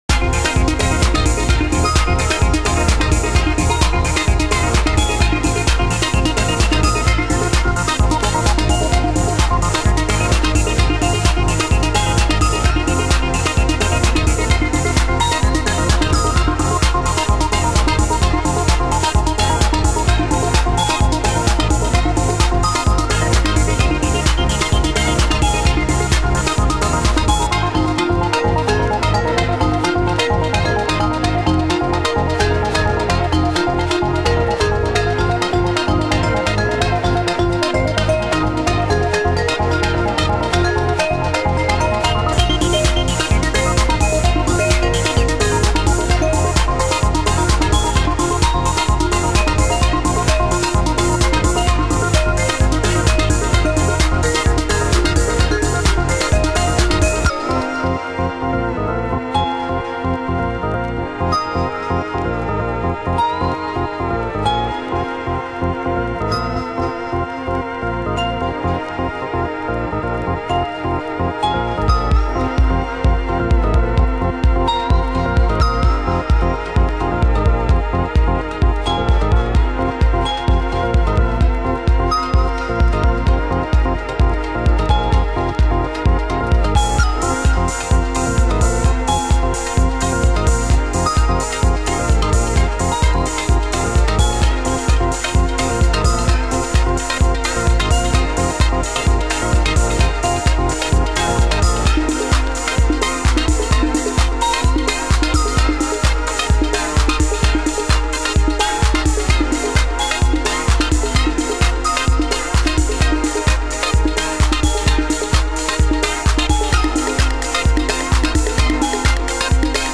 ○B1にプレスが原因の針飛び有
チリパチノイズ有/白い素材が混ざるマーブル盤/NSCスタンプ！